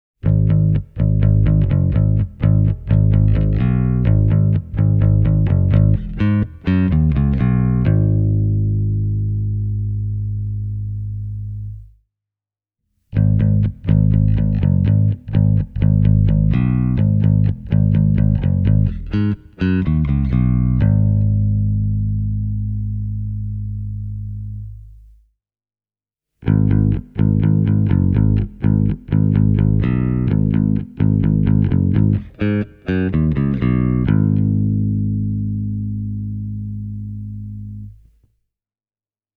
A Fender Adam Clayton Signature Jazz Bass with a Mojotone 70s Clone JB Set.